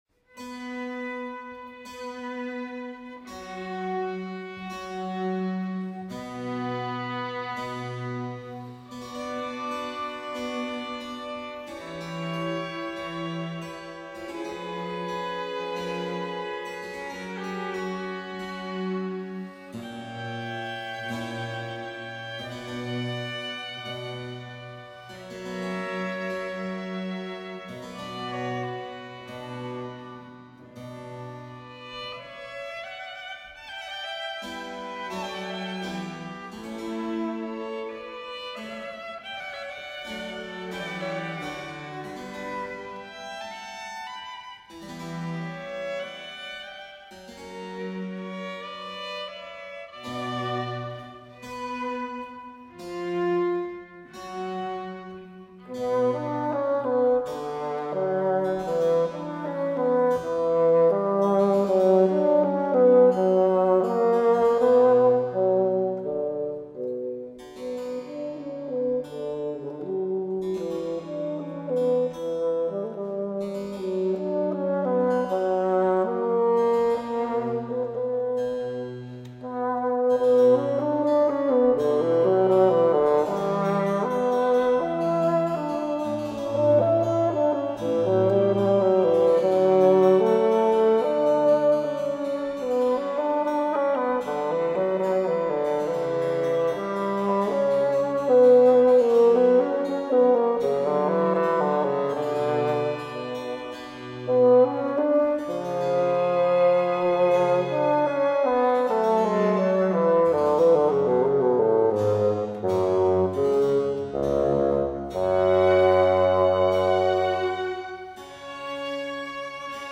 Antonio Vivaldi Concerto für Fagott, Streicher und Continuo in e-moll RV 484 Live-Aufnahme mit dem Bamberger Streichquartett